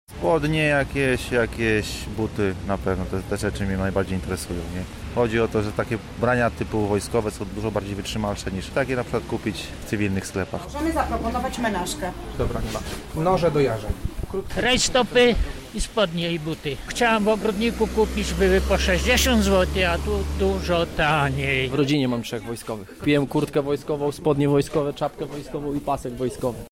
Czekających w kolejce i robiących pierwsze zakupy zapytaliśmy, dlaczego zdecydowali się na nabycie danych przedmiotów.